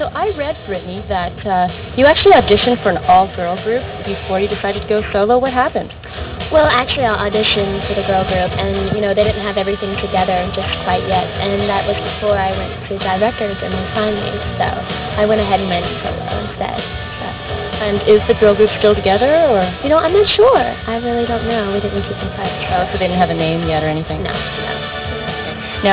.WAV of Britney talking like she